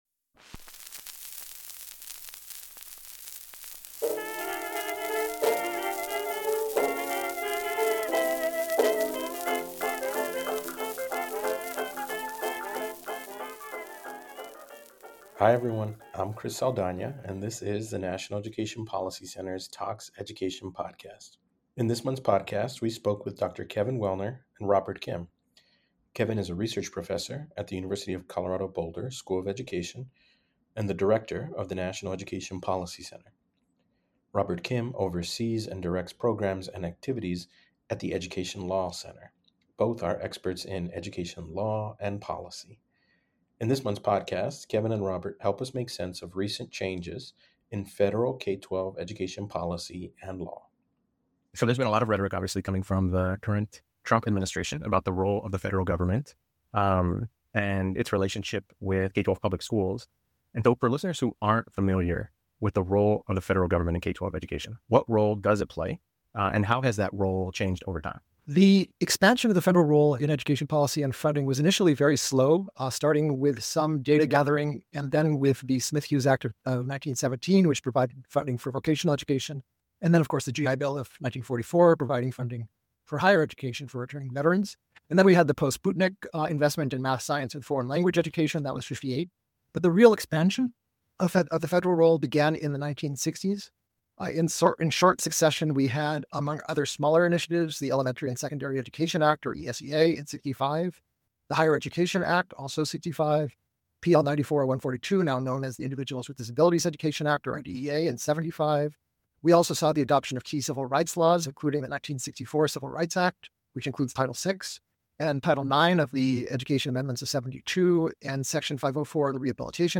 Education Interview